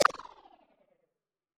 Click Back (7).wav